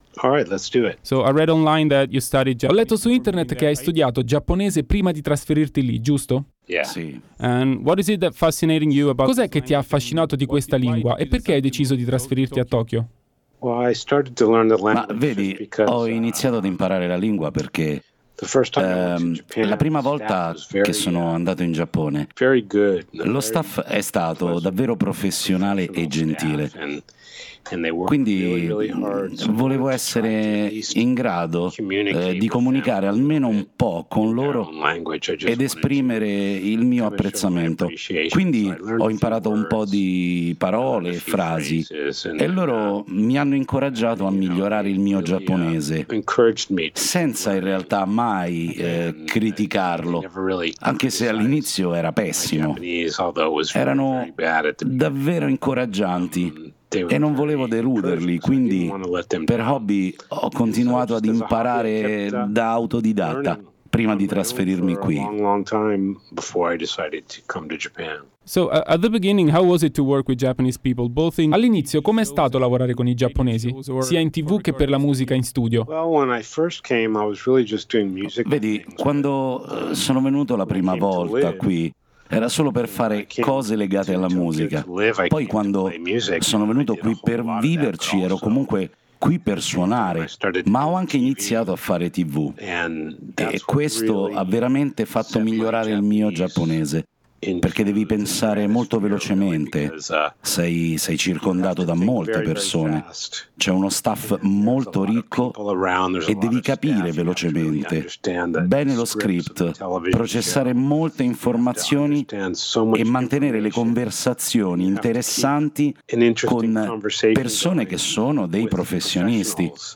Intervista Marty Friedman | Radio Città Aperta